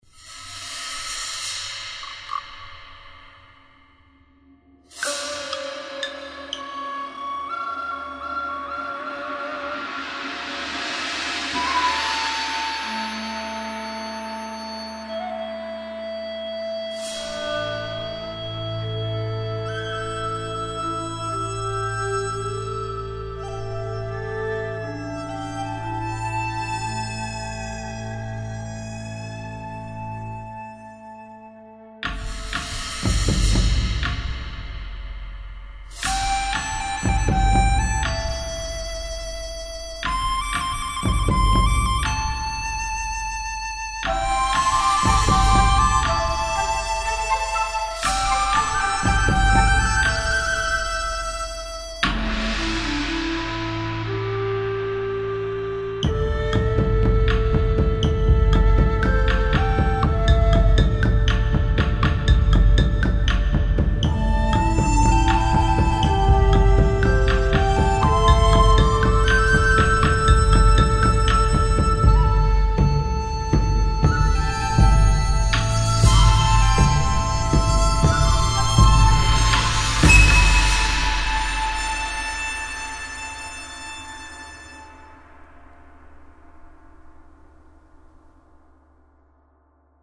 (flûte brillante)
/note Compo5 : S'Hume Ô Tôt Riz____ Francois (jeu de mot à-la-con, vst ethniques)
Bon c'est plus chinois que japonais, mais on ne va pas chipoter. Je regrette un peu par contre que tu n'aies pas joué plus sur leur pan, là ils sont tous un peu sur les autres.
L'arrivée des nappes de corde est sympa et aurait méritée d'être plus exploitée (que d'aller au cinéma).